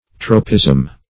Tropism \Tro"pism\ (tr[=o]"p[i^]z'm), n. [Gr. troph` a turning,